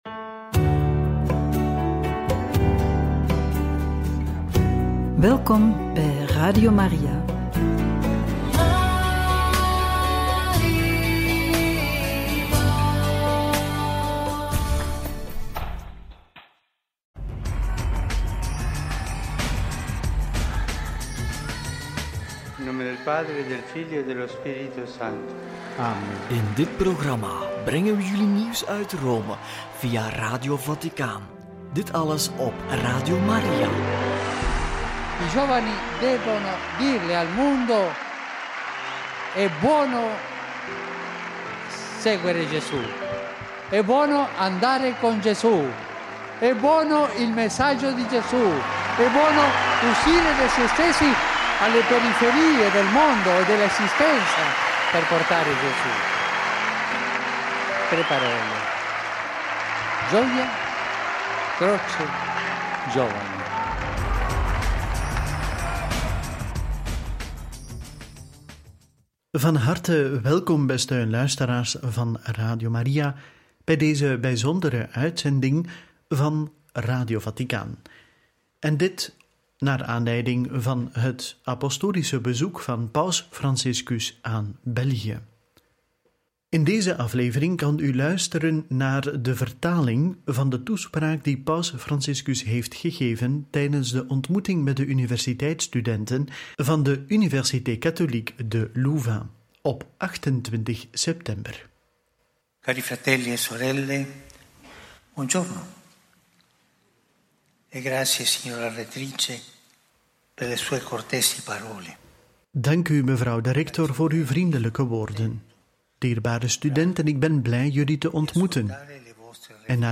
28/9 Paus Franciscus ontmoet studenten aan de Université Catholique de Louvain in Louvain-la-Neuve – Radio Maria